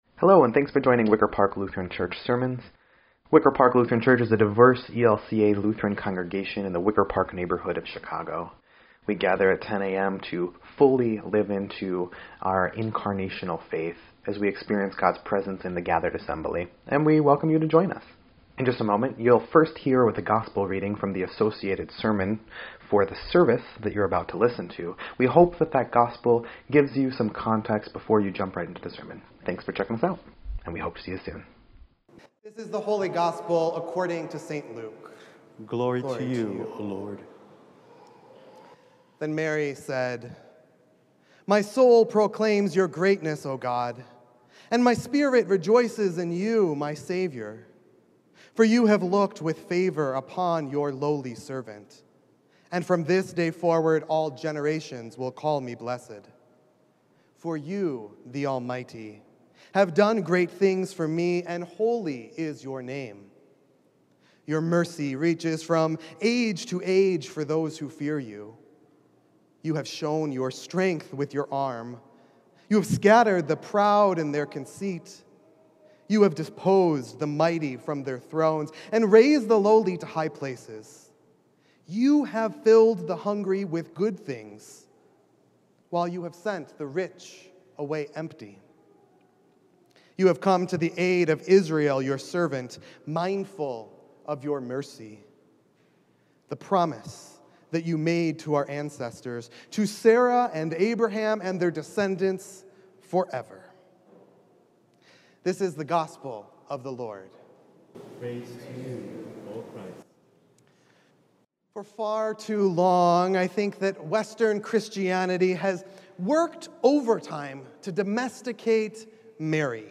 8.15.21-Sermon_EDIT.mp3